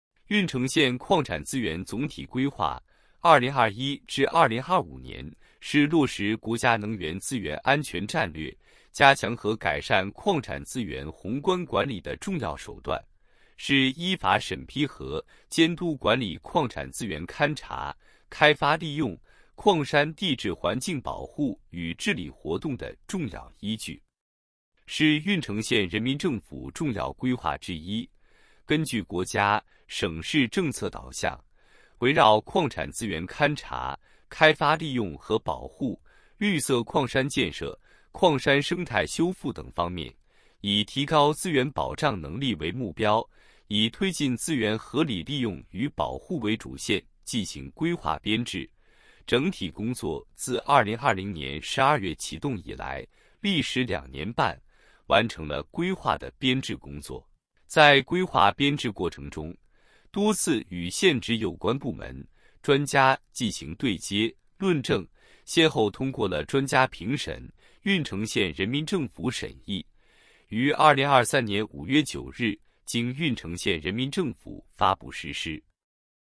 • 分　　类：音频解读